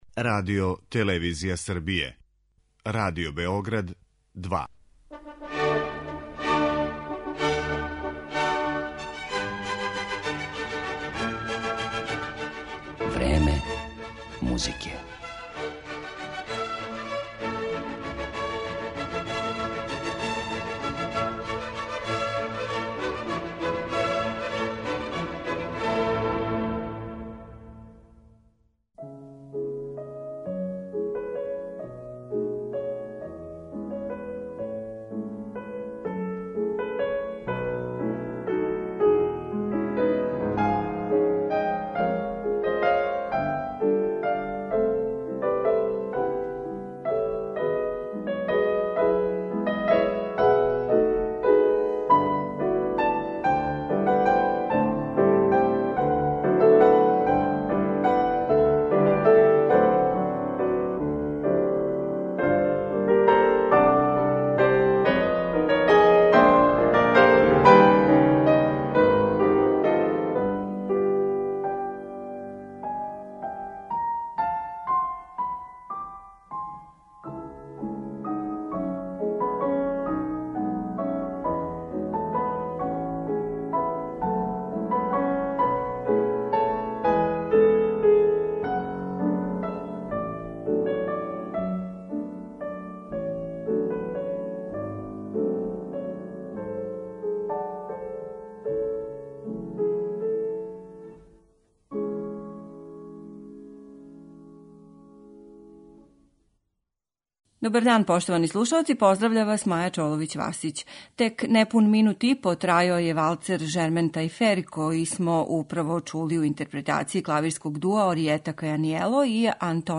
Композиције за два клавира